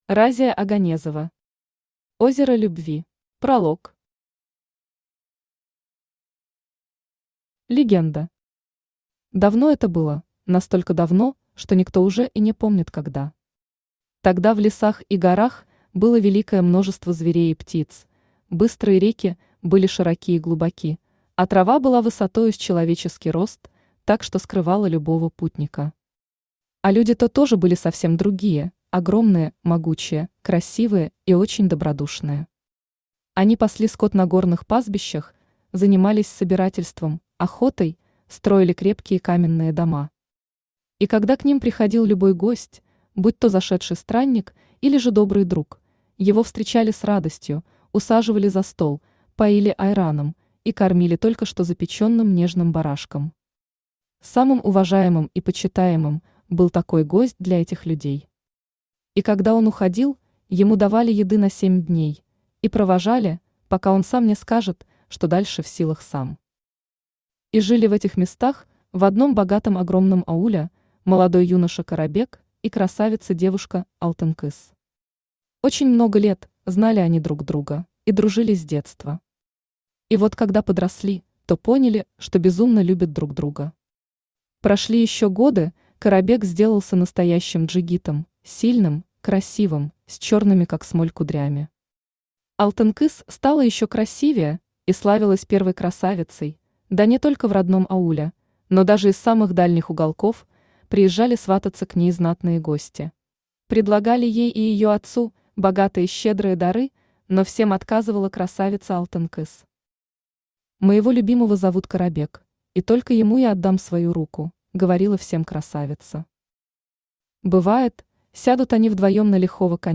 Аудиокнига Озеро любви | Библиотека аудиокниг
Aудиокнига Озеро любви Автор Разия Оганезова Читает аудиокнигу Авточтец ЛитРес.